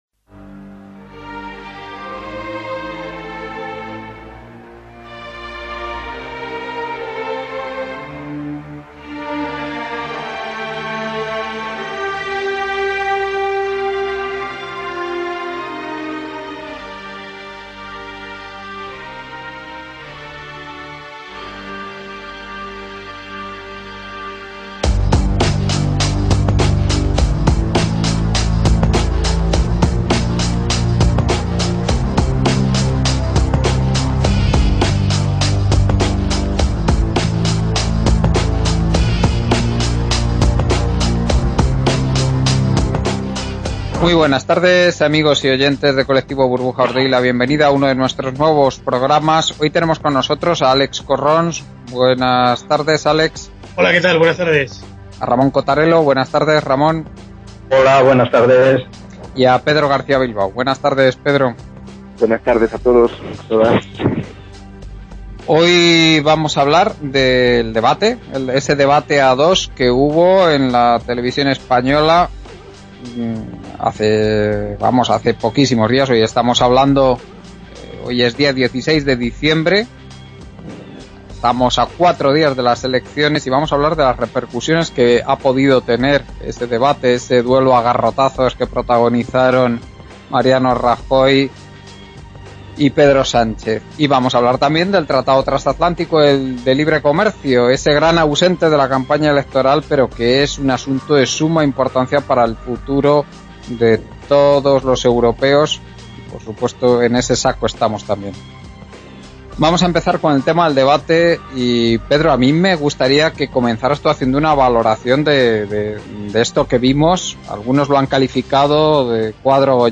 Debate radio Colectivo Burbuja – Debate a dos y el TTiP- Diciembre 2015